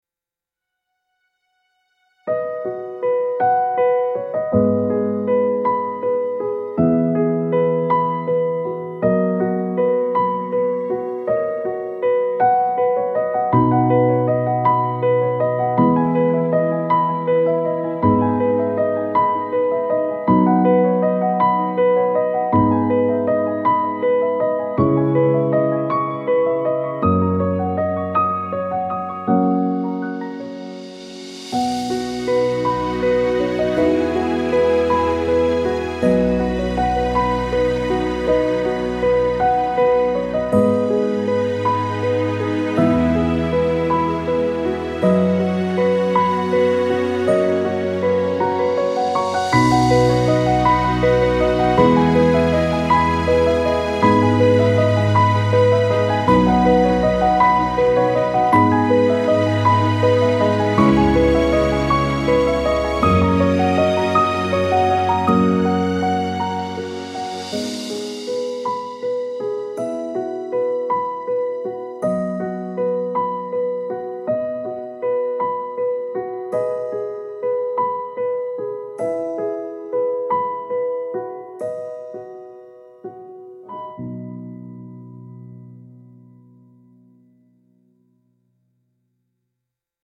grateful celebration of life music with uplifting piano and gentle optimism